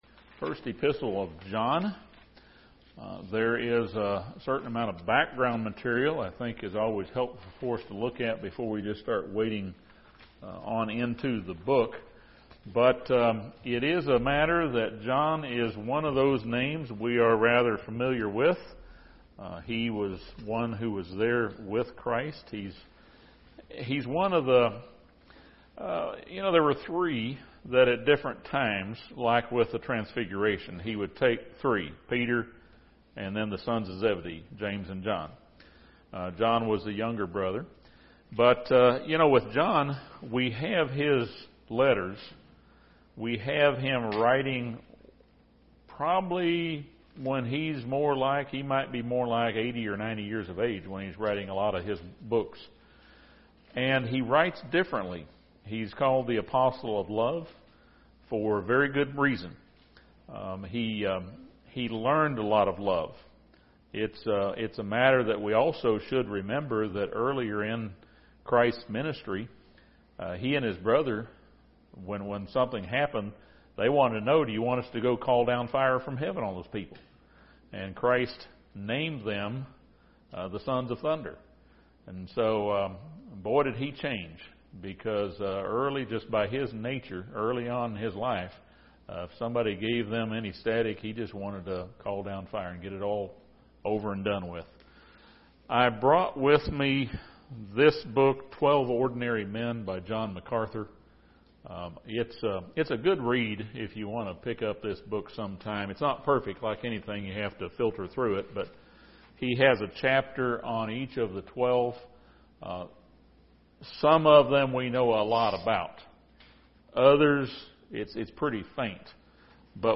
This Bible study covers background information to 1 John. It also surveys chapters 1 and 2.